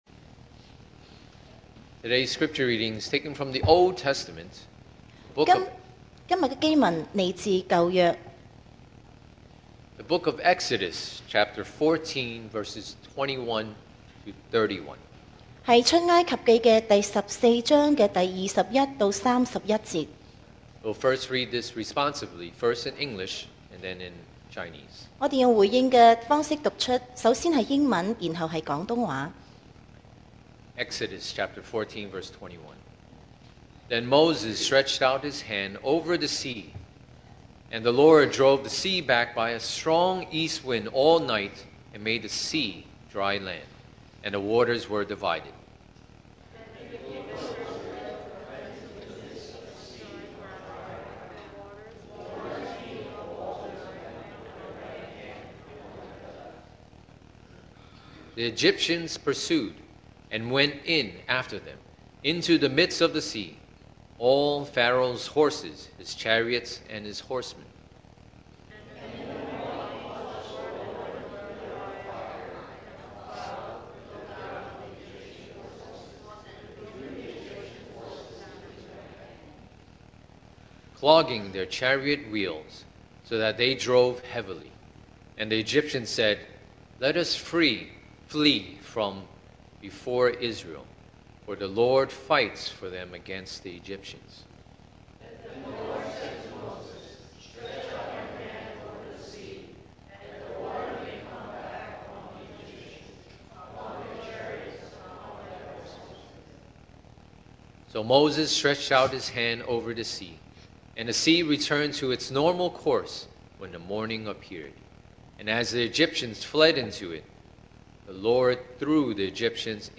Series: 2024 sermon audios
Service Type: Sunday Morning